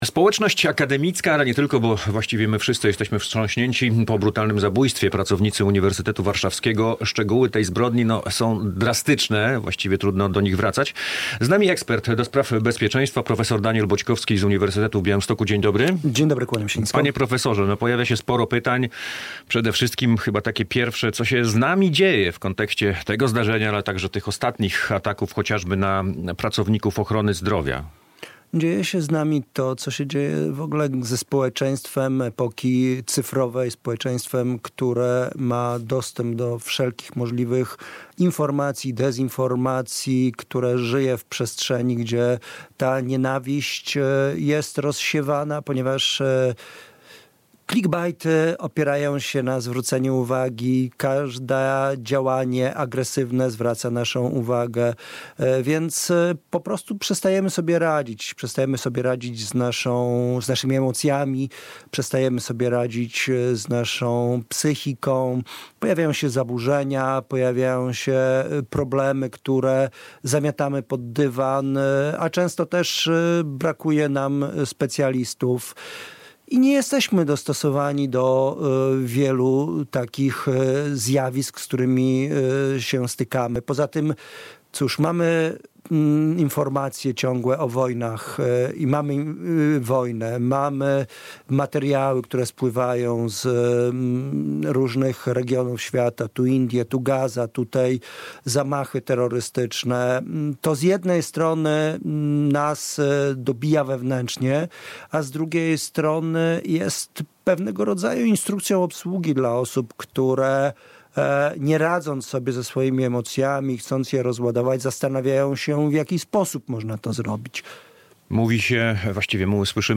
o tragedii na Uniwersytecie Warszawskim rozmawia